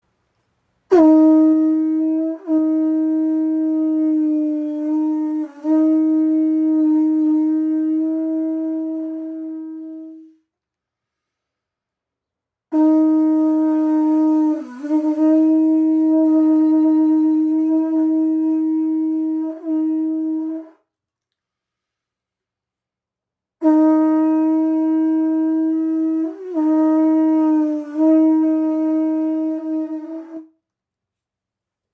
Muschelhorn | Pūtātara | Shankha | Conch Shell | Kavadi | Conque | ca. 24 cm